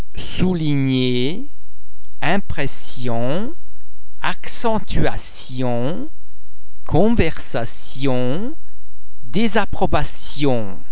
In words with more than 2 syllables there tend to be a secondary stress on the very first syllable in addition to the primary which falls on the last syllable.